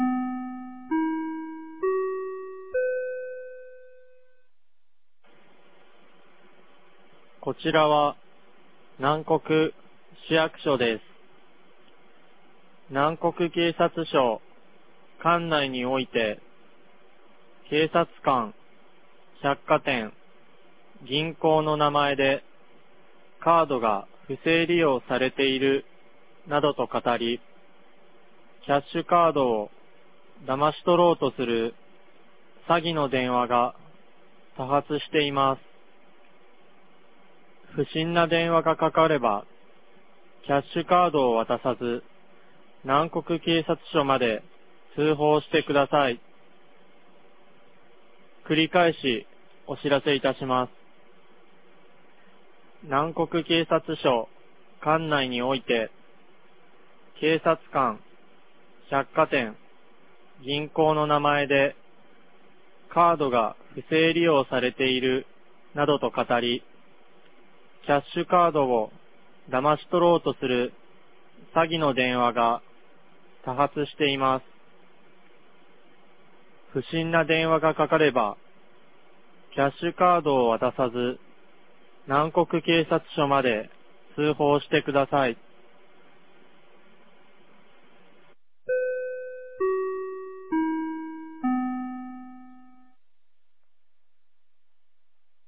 2021年10月25日 16時21分に、南国市より放送がありました。
放送音声